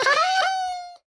豹猫死亡时随机播这些音效
Minecraft_ocelot_death1.mp3